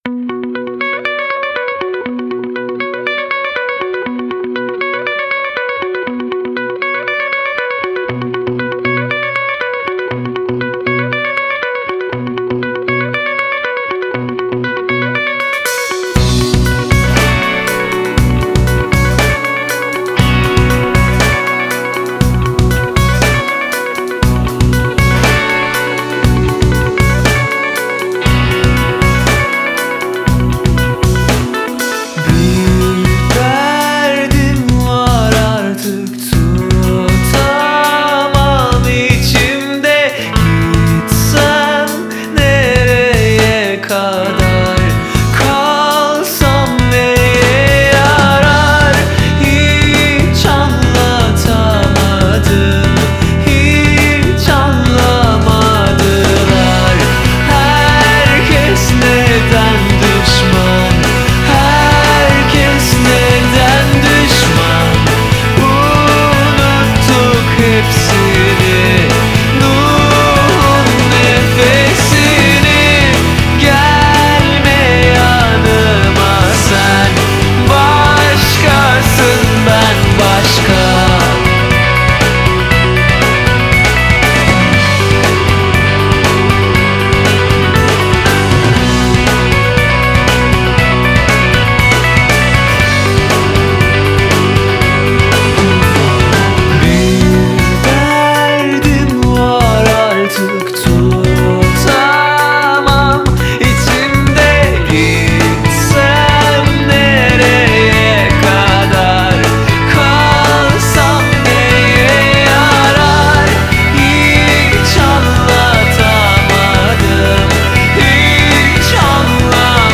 یکی از آهنگ های راک و جذاب ترکیه ایه